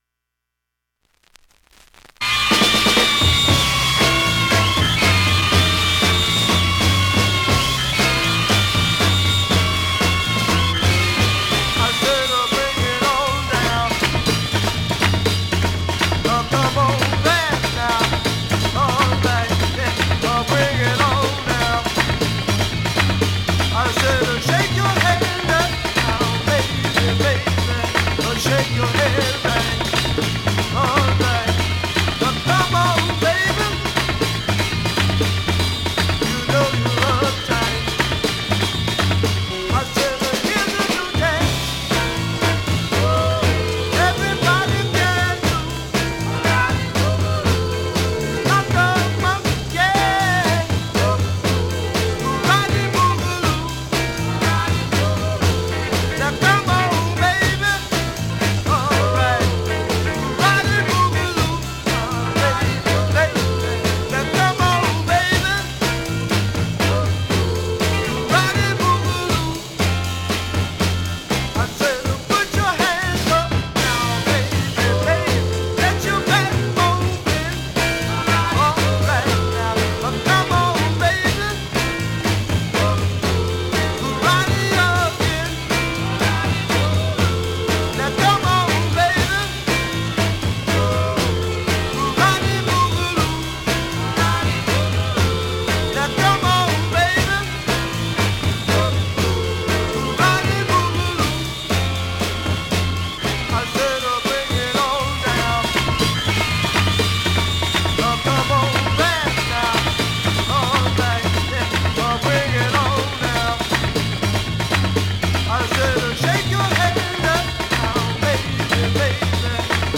現物の試聴（両面すべて録音時間4分48秒）できます。
ハリスバーグ発のフィリー・ファンク・バンド グルーヴィーなオルガンが最高のカラテシリーズ